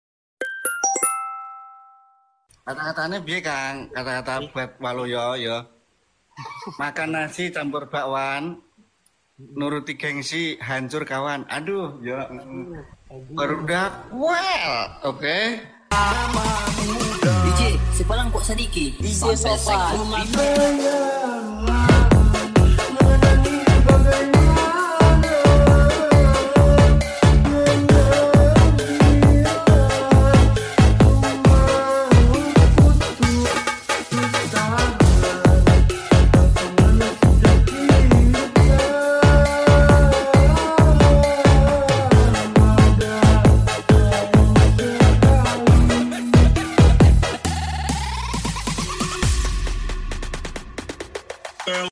Genre: Nada dering remix